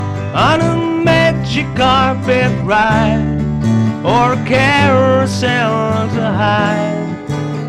Soften Harsh Voice In a song
I recorded a song of a friend in 1970 on a multitrack reel-to-reel, this was digitized to MP3 by a friend in 2016.
The guitar track and vocals are now combined into a stereo format, and each of the left and right channels are identical.
The vocals seem harsh to me and I want to soften these, basically, I want to reduce the level of nerve-twanging intensity they induce.